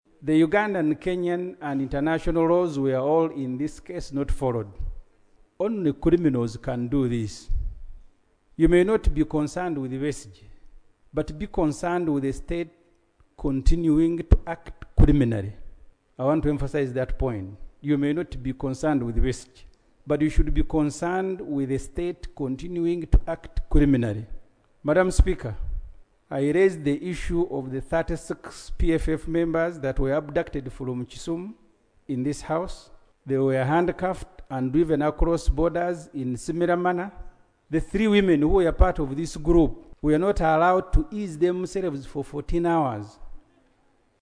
During the plenary sitting on Tuesday, 26 November 2024, legislators questioned the country's adherence to international and regional laws following the arrest of the Opposition leader.